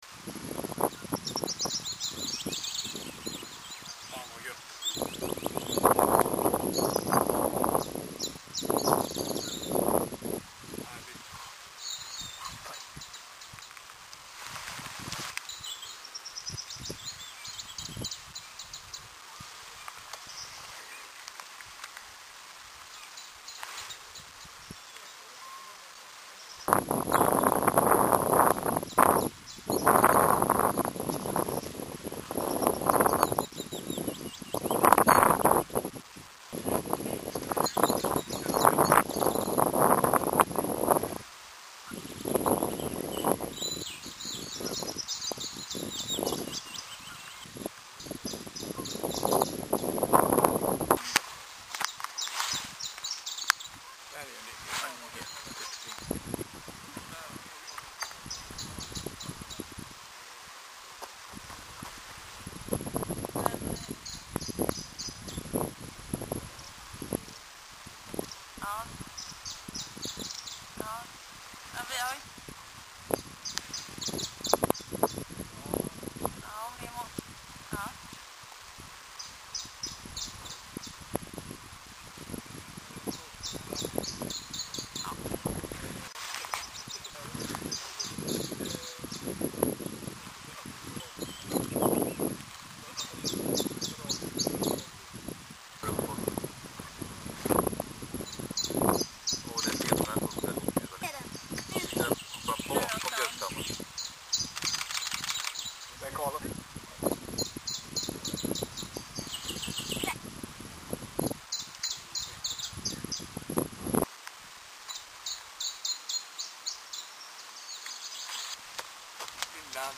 En distad, brusig och allmänt risig inspelning av dagens iberiska gransångare på Vrångö finns nu att avlyssna här:
Iberisk gransångare, Vrångö
Man kan notera att den inte sjunger ut helt i alla strofer, utan ibland utelämnar slutknorren.